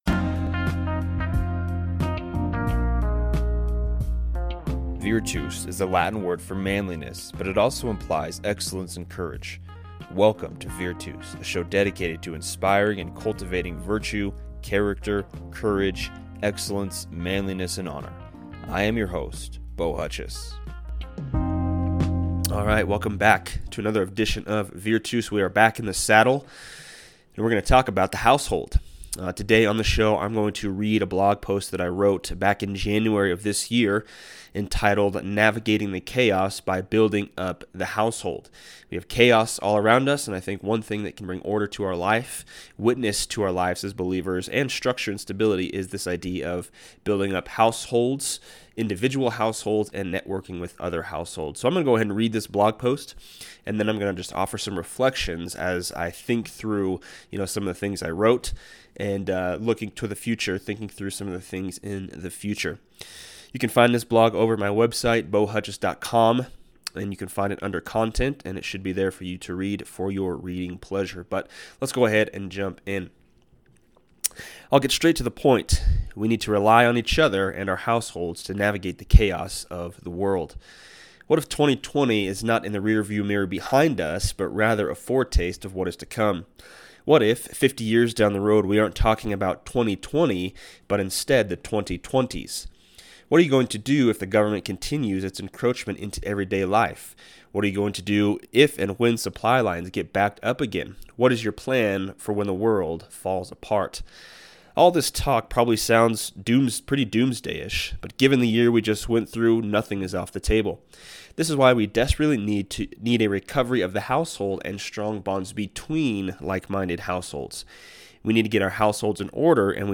Today I read from my own blog post 'Navigating the Chaos by Building Up Households' and reflect on our current moment in history.